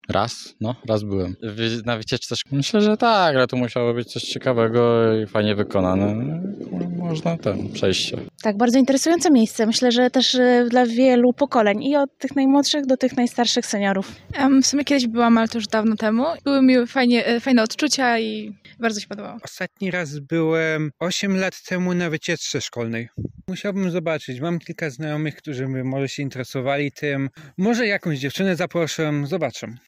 Spytaliśmy studentów, czy byli kiedyś w Teatrze im. Jana Kochanowskiego i co mogą opowiedzieć o tym przedstawieniu:
dzwiek-sonda.mp3